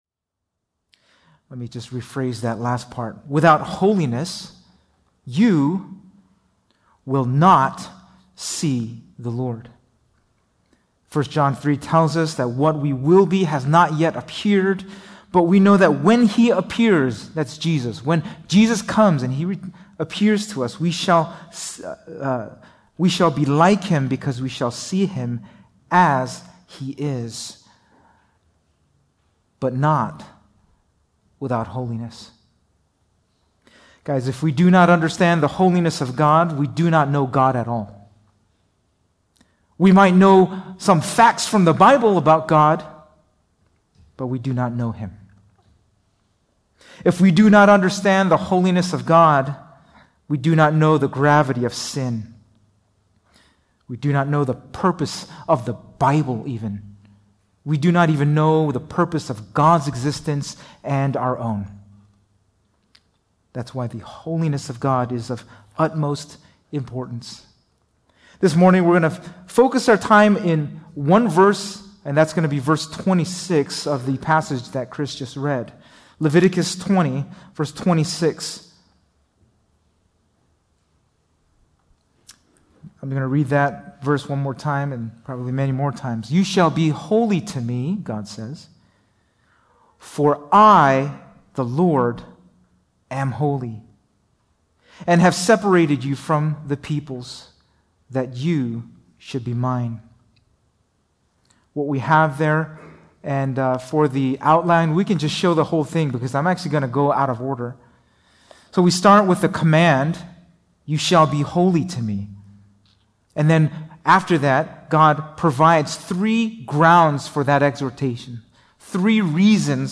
2024 (Sunday Service)Bible Text